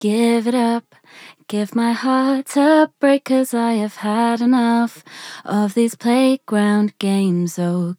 名前からじゃ何か良くわからないかと思いますが、コーラスや、フランジャー、リバーヴ、ダブラーなどをこれ一個のプラグインで実現しています。
自分の場合ですが、ボーカルにかける場合、ダブラーがボーカルをより印象的な音にする為のエフェクターだとしたら、コーラスは横に広げる目的でかけています。